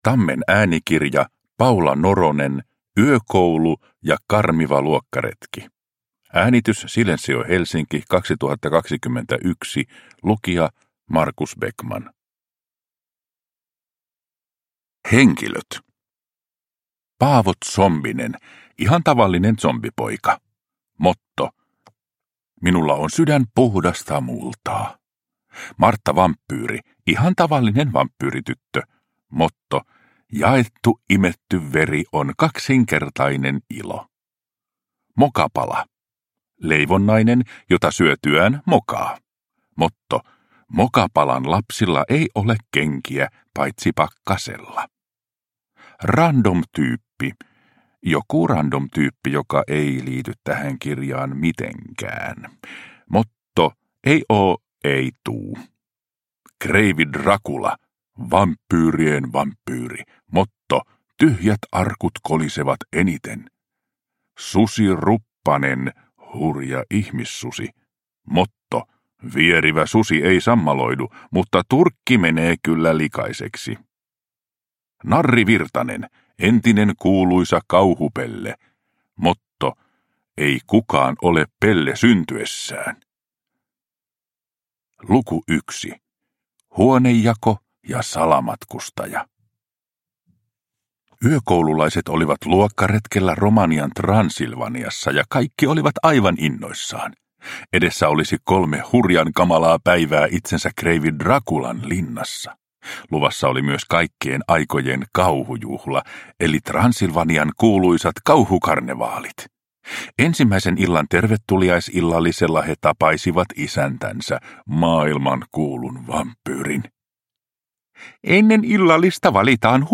Yökoulu ja karmiva luokkaretki – Ljudbok